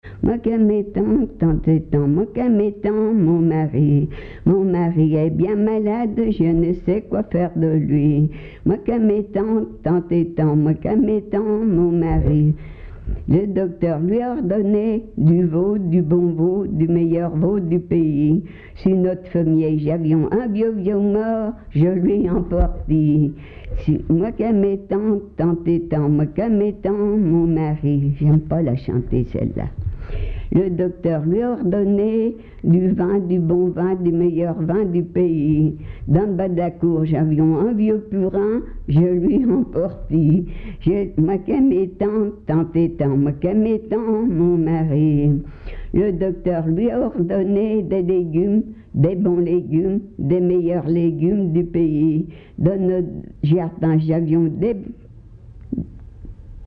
Genre laisse
Enquête Le Havre en chansons
Pièce musicale inédite